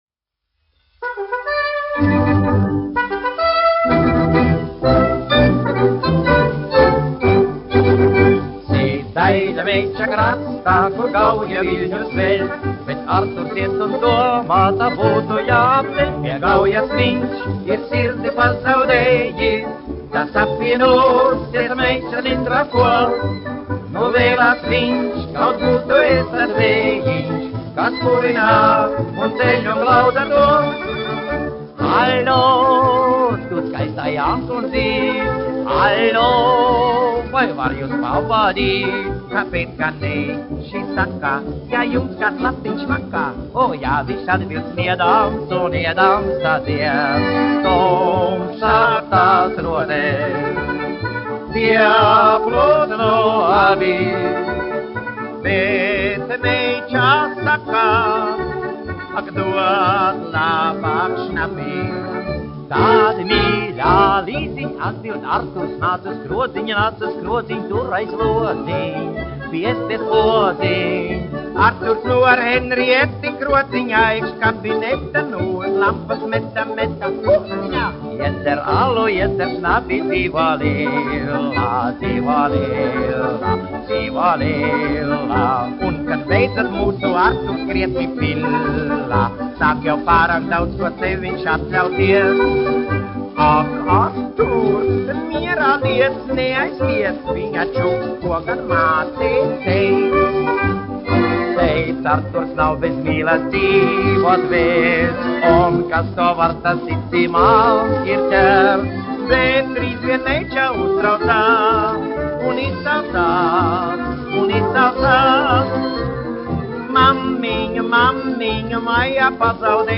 1 skpl. : analogs, 78 apgr/min, mono ; 25 cm
Populārā mūzika
Marši
Fokstroti
Skaņuplate